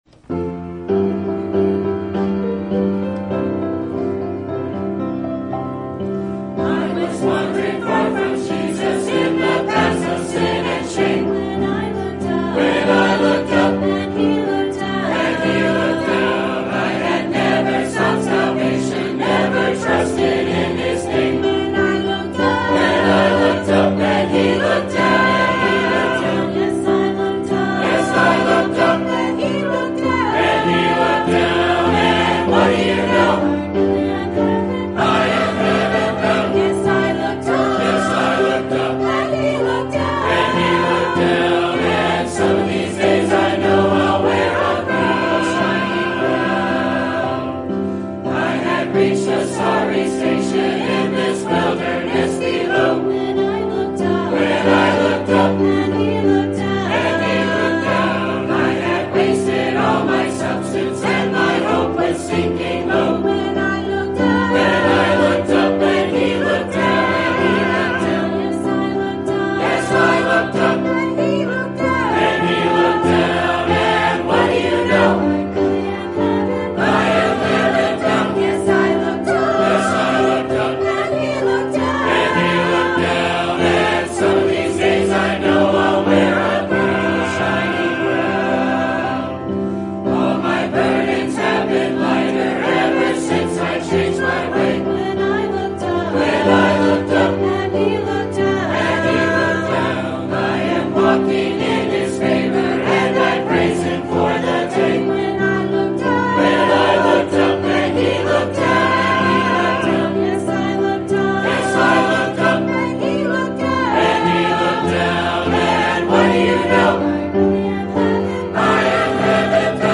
Choir